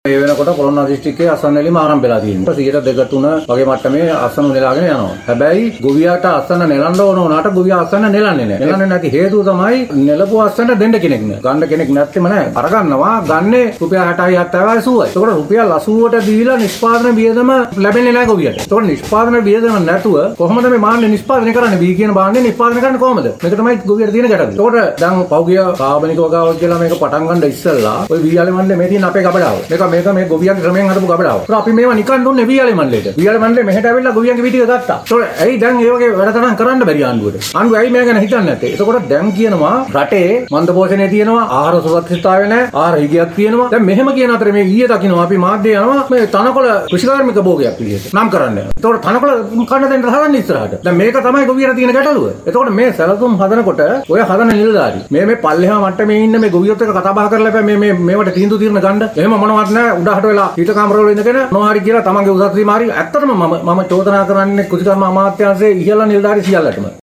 ඔවුන් මේ බව සදහන් කලේ පොලොන්නරුව පරාක්‍රම සමුද්‍රය ගොවි සංවිධාන කාර්යාලයේ දී ඊයේ මාධ්‍ය හමුවක් කැඳවමින් .